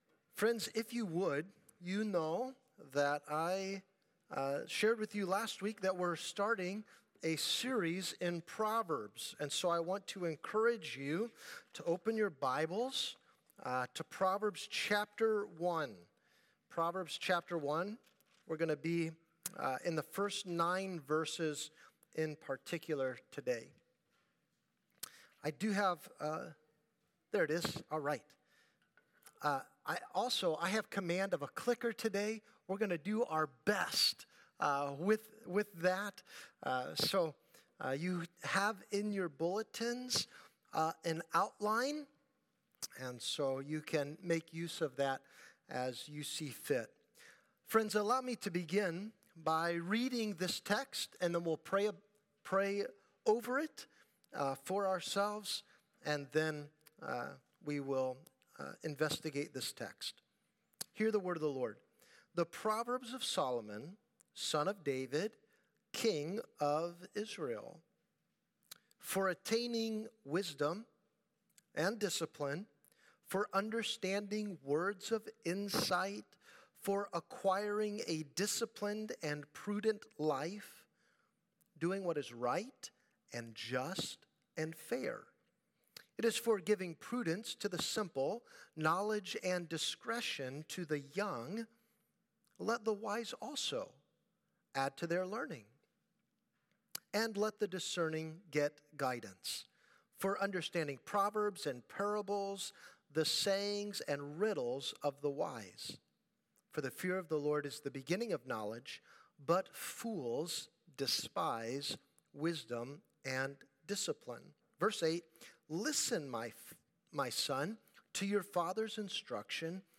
Sermons | California Road Missionary Church